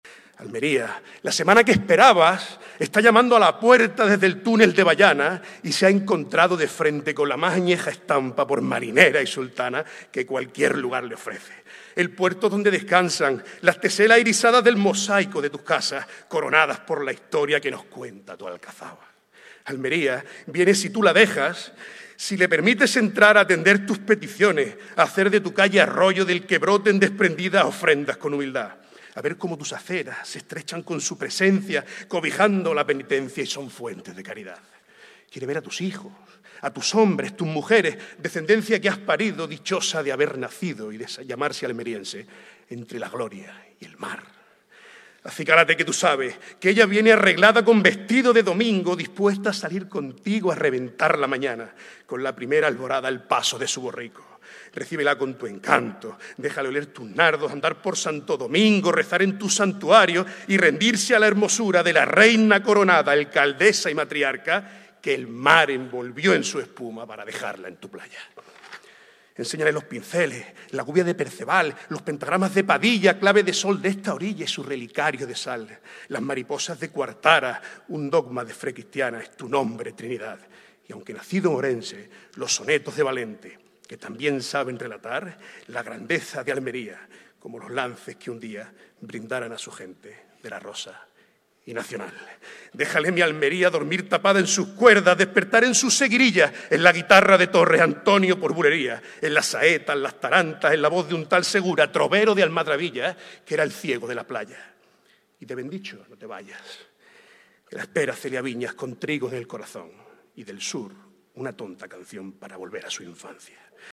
La alcaldesa, María del Mar Vázquez, ha asistido a la lectura que por primera vez se ha realizado en un Teatro Cervantes que, con entrada libre, se ha llenado de sentimiento y devoción